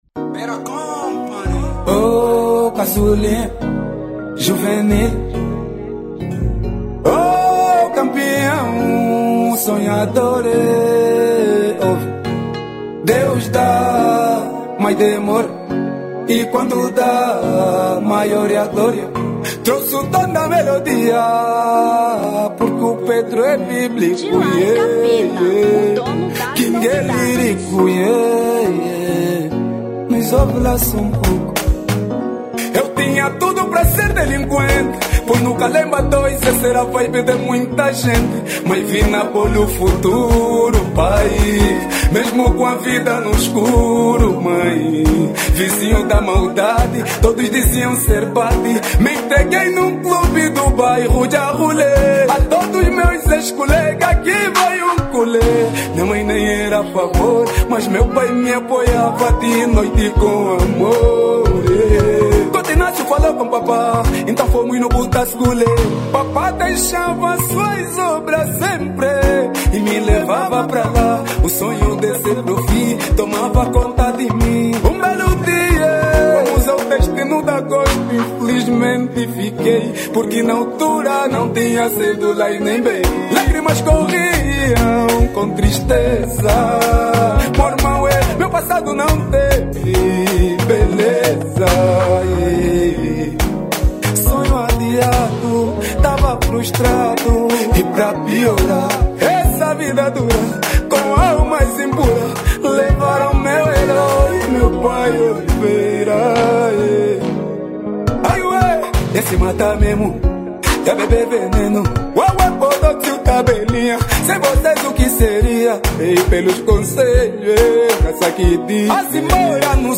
Kuduro 2022